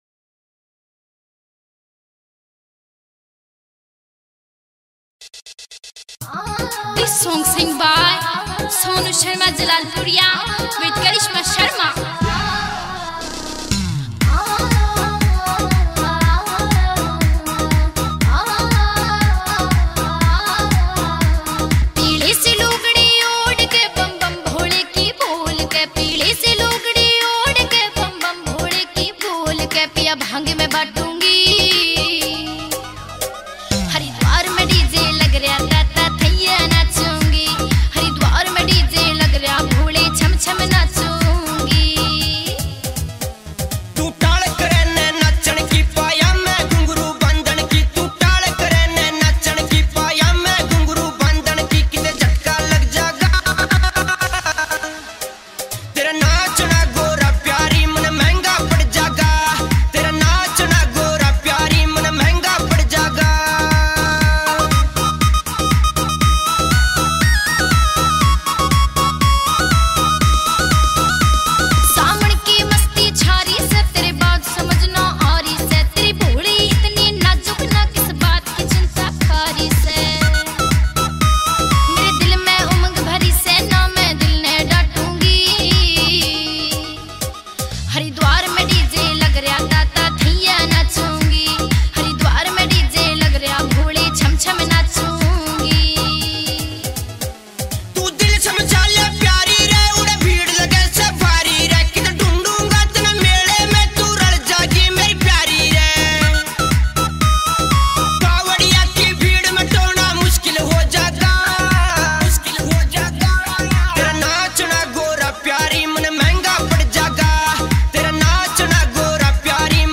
best Haryanvi Bhole Baba Bhajan
Sawan Shiv Bhajan